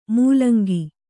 ♪ mūlangi